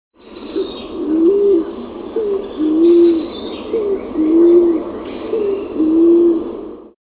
Columba speciosa (scaled pigeon)
This sample was taken near Puerto Viejo de Sarapiqui, a few miles from La Selva (Costa Rica), 6/19/99.